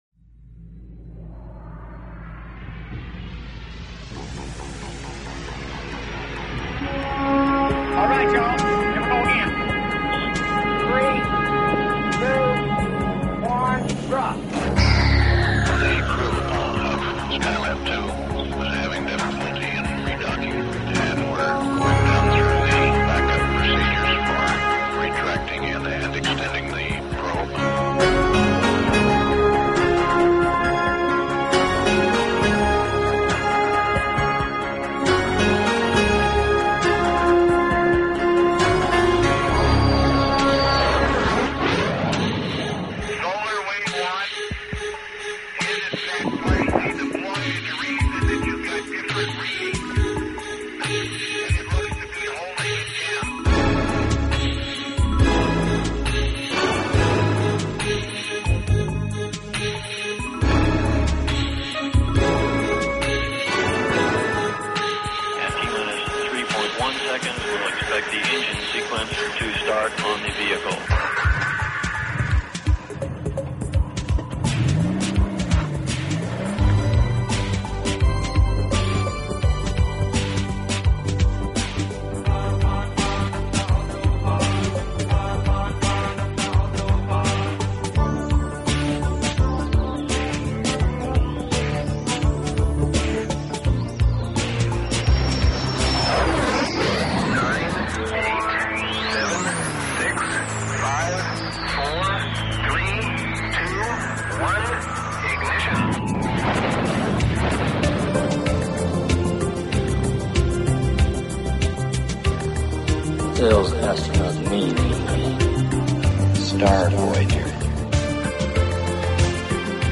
Talk Show Episode, Audio Podcast, ET-First_Contact_Radio and Courtesy of BBS Radio on , show guests , about , categorized as
Emotional Talk during a walk in The Hague, The Netherlands